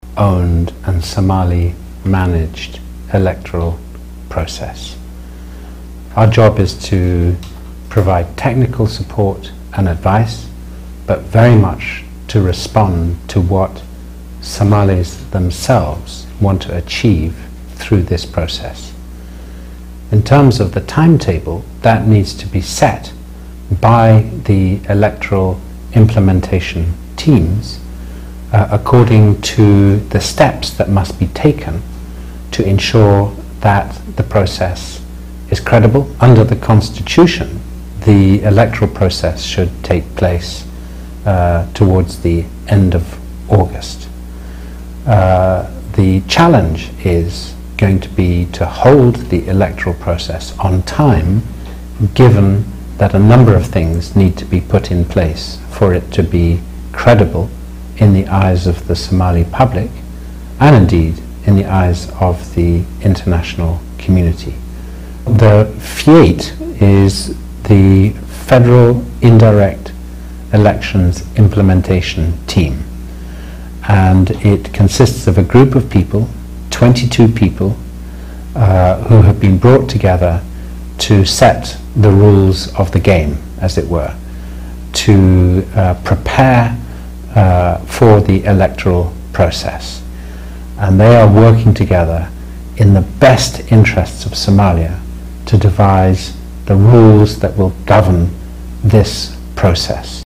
Dhegeyso Ergeyga Qaramada Midoobey u Qaabilsan Somaliya oo Ka Hadley Doorashooyinka 2016